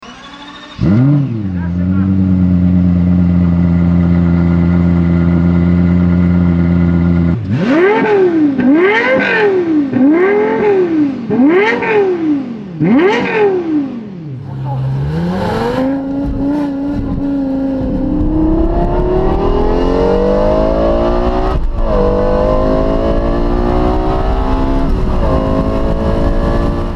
ENGINE SIZE 4.8L V10